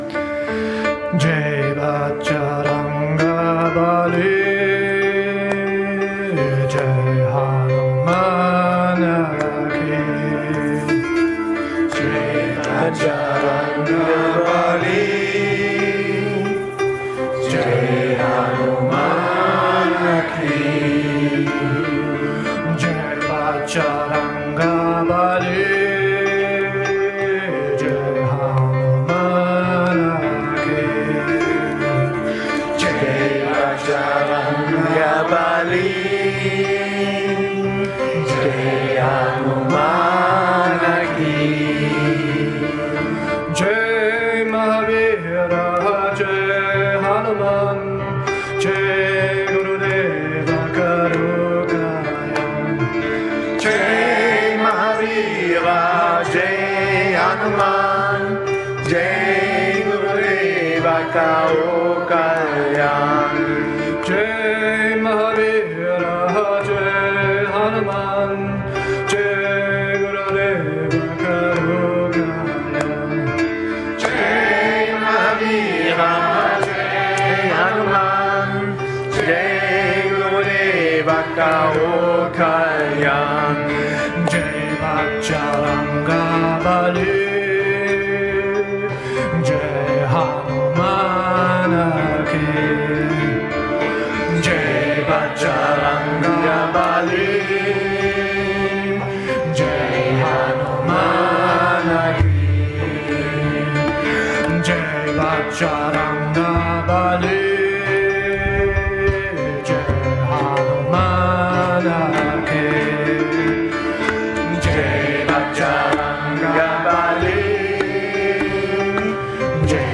during a kirtan concert
in Bad Meinberg, Germany